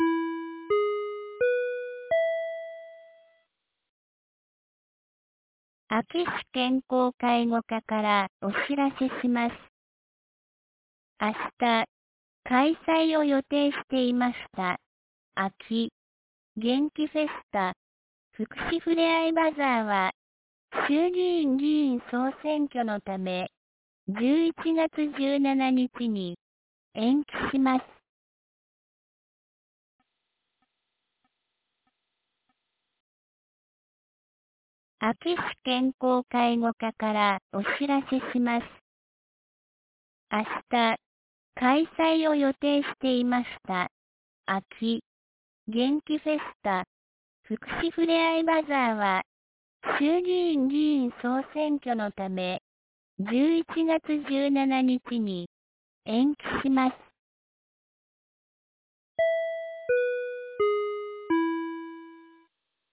2024年10月26日 17時31分に、安芸市より全地区へ放送がありました。